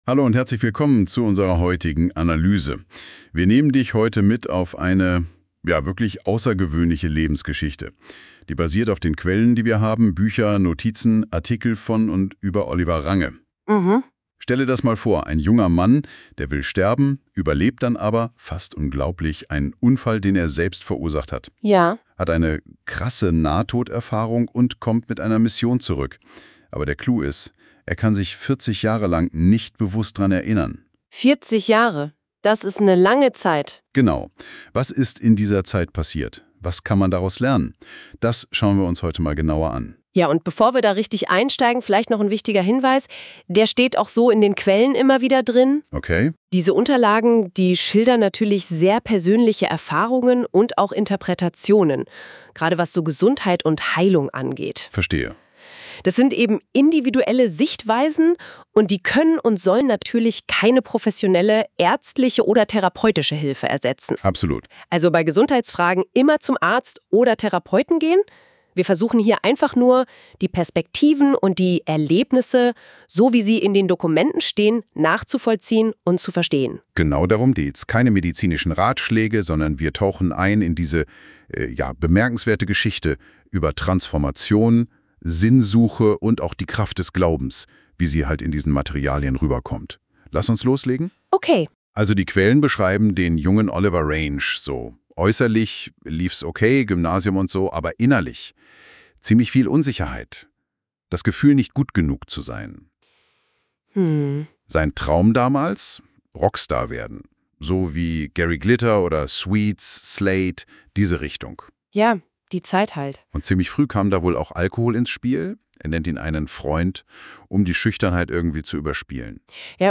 In diesen Gesprächen hörst du zwei Stimmen,die über mein Leben sprechen.